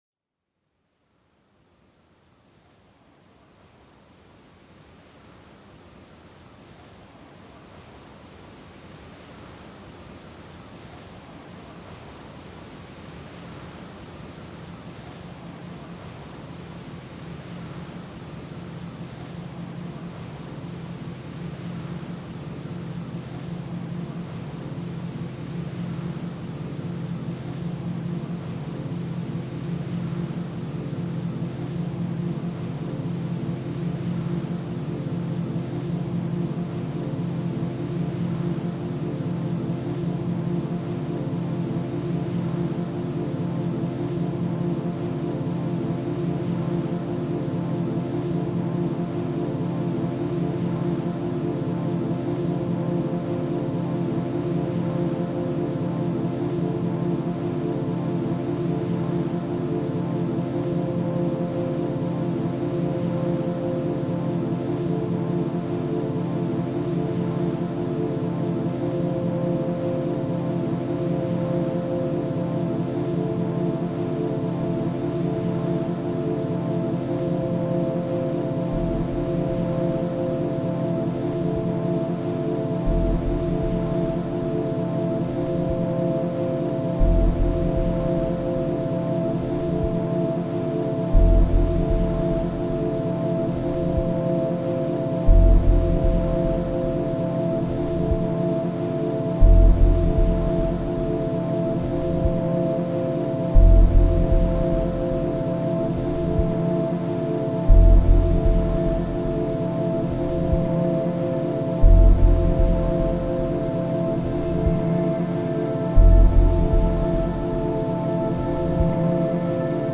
Sublime ambient drones accentuated by muted percussion